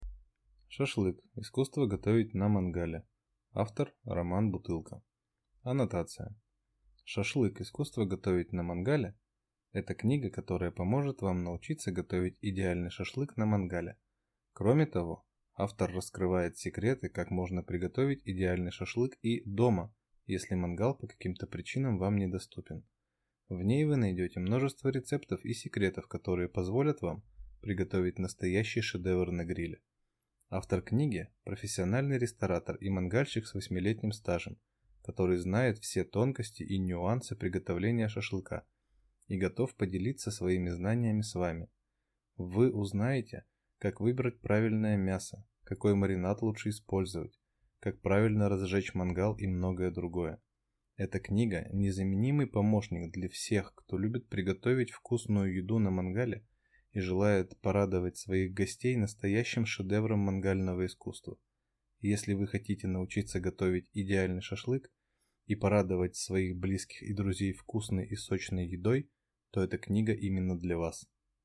Аудиокнига Шашлык. Искусство готовить на мангале | Библиотека аудиокниг